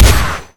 shotgun.ogg